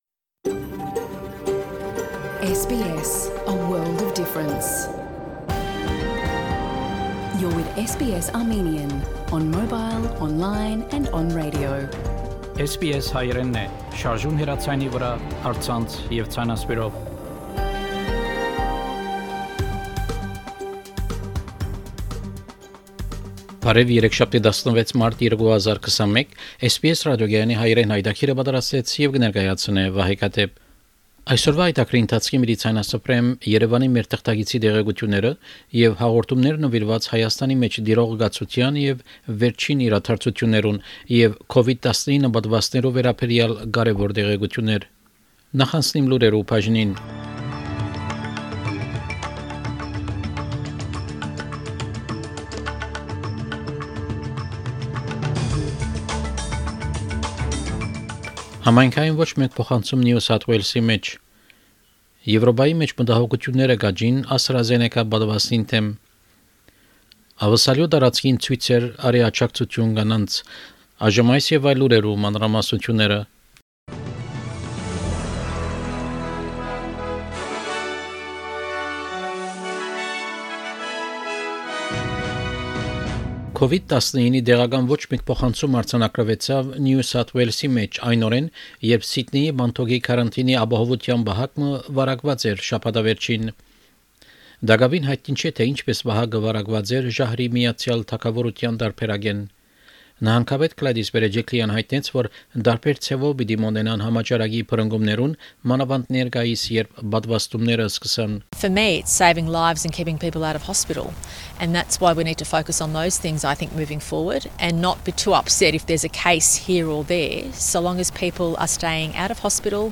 SBS Armenian news bulletin – 16 March 2021
SBS Armenian news bulletin from 16 March 2021 program.